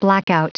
Prononciation du mot : blackout